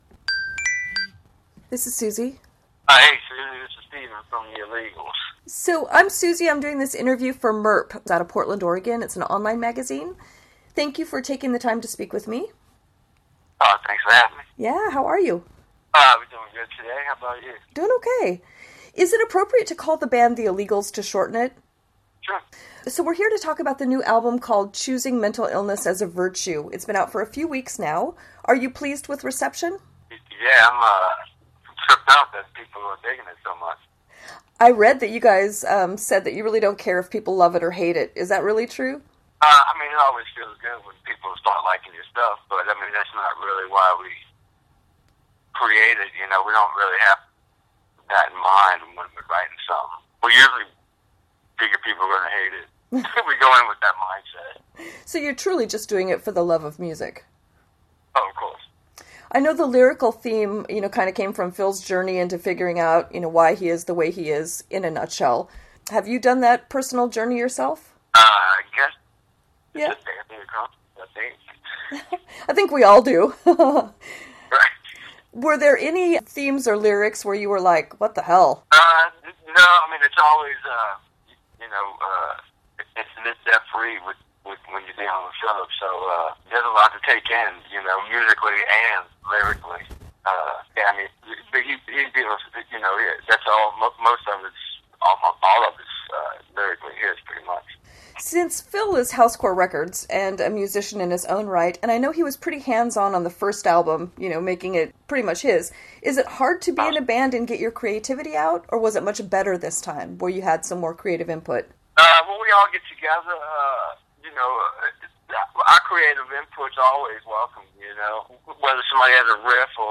Venue: Portland, Oregon
Topic: Interview